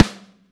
Snare 12.wav